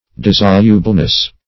Search Result for " dissolubleness" : The Collaborative International Dictionary of English v.0.48: Dissolubleness \Dis"so*lu*ble*ness\, n. The quality of being dissoluble; dissolubility.
dissolubleness.mp3